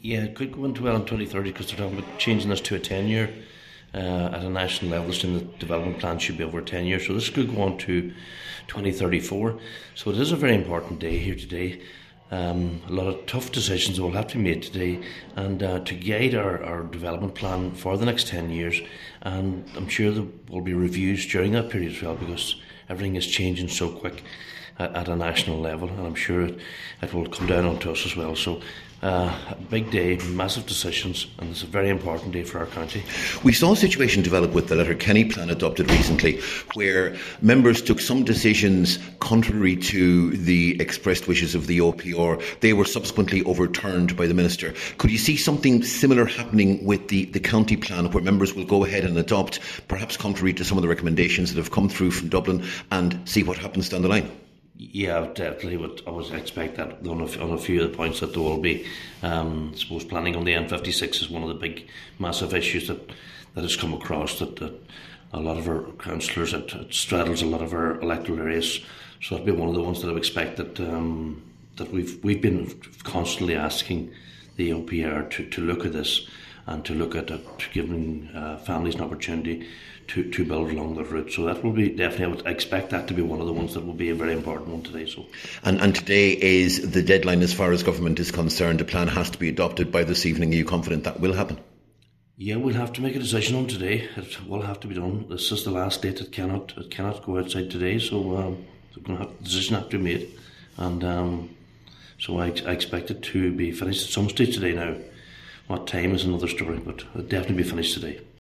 Cathaoirleach Cllr Martin Harley says today’s meeting is a very important one which must lead to a decision, particularly as new planning laws may extend the plan’s timeframe to 10 years……..